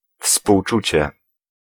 Ääntäminen
UK : IPA : /kəm.ˈpæʃ.ən/